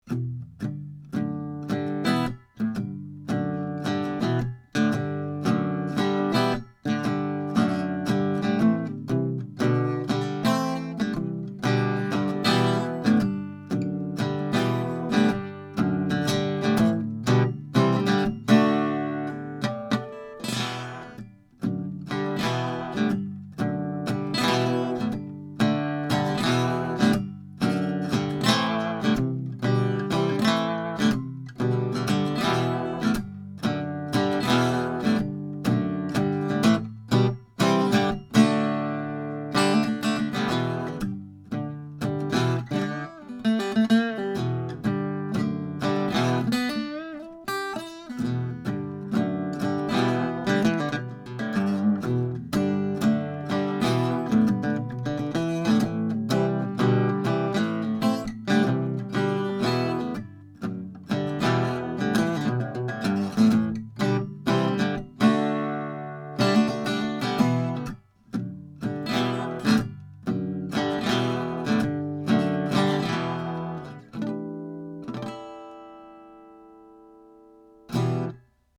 Tracked through a pair of Warm Audio WA12 preamps, into a Metric Halo ULN-8 interface, no compression, EQ or effects:
1933 GIBSON L10 ARCHTOP